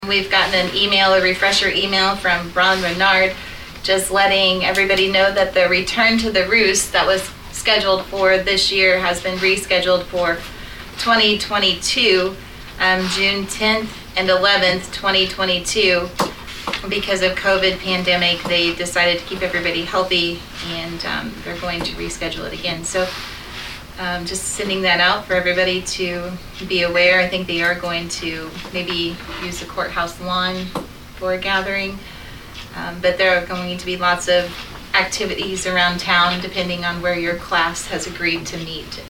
During the meeting of the Saline County Commission on Thursday, January 7, Northern District Commissioner Stephanie Gooden gave the details about “Return to the Roost”- an event for Marshall High School graduates.